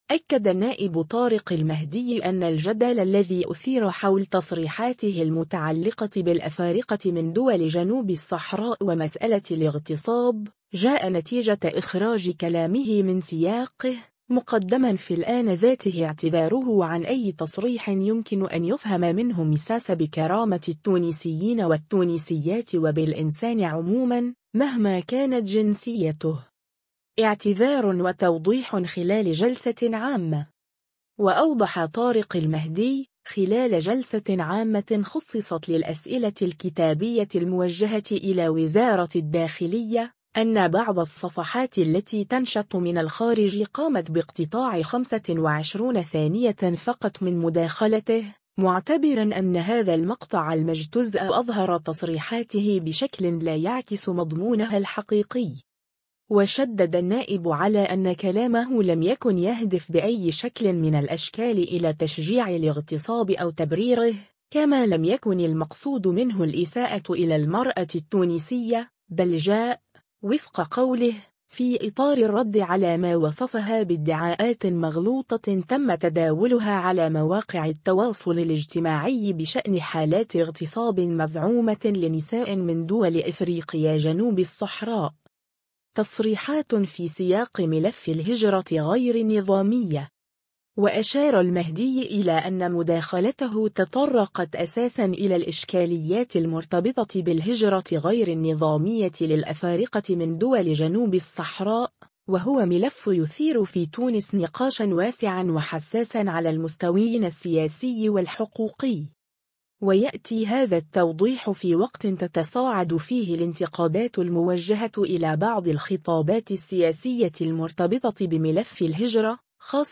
اعتذار وتوضيح خلال جلسة عامة